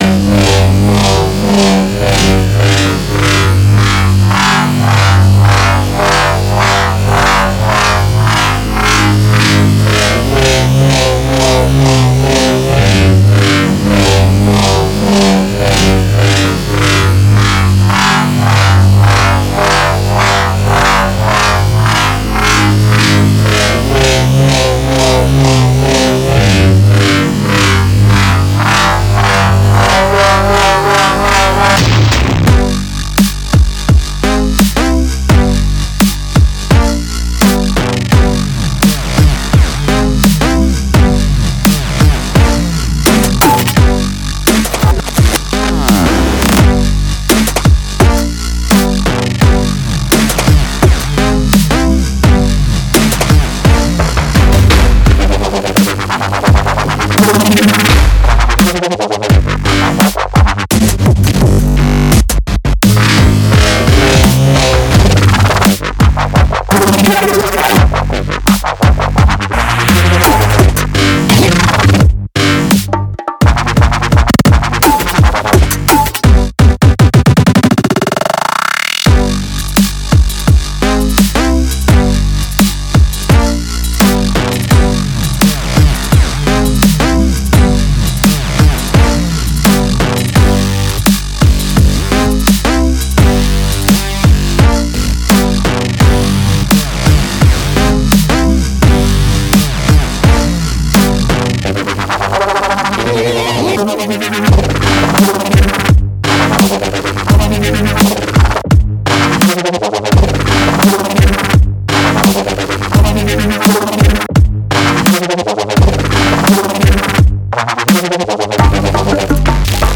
mp3,5003k] Электронная